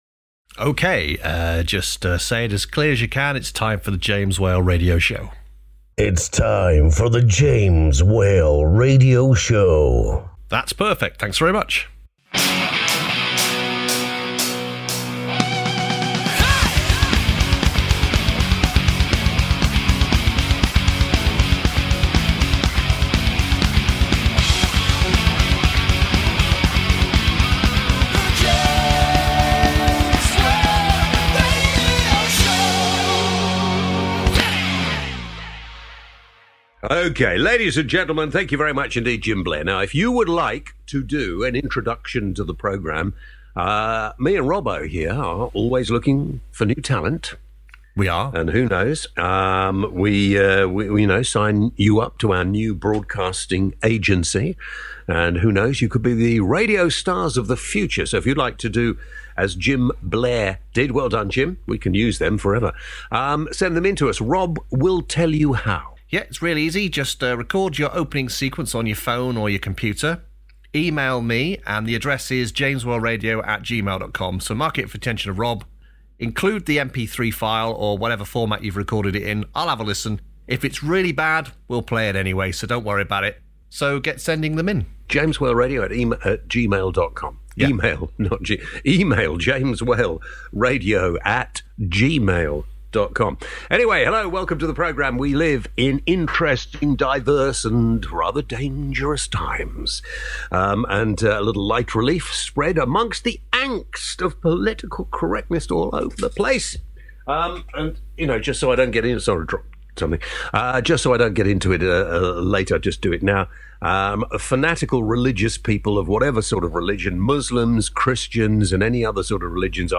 The James Whale Radio Show.